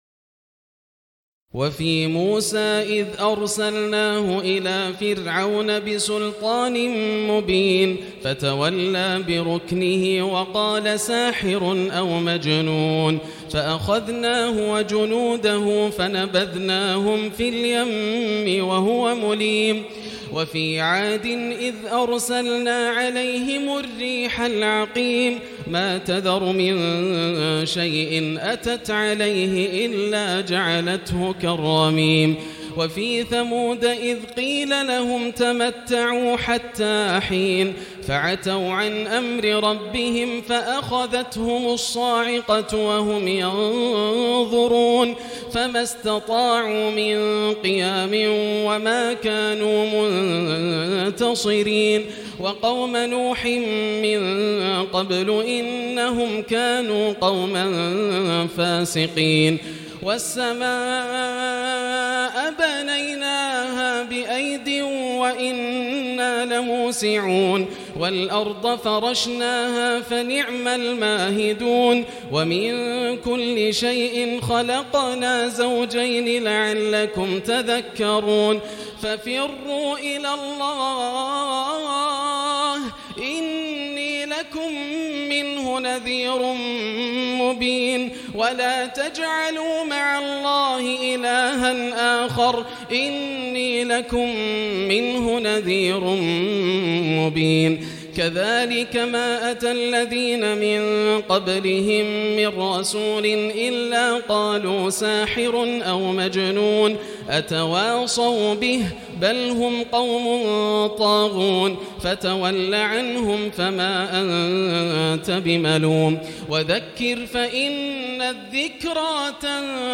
الليلة السادسة والعشرون - من خواتيم الذاريات38-60 وسور الطور-النجم- القمر > الليالي الكاملة > رمضان 1439هـ > التراويح - تلاوات ياسر الدوسري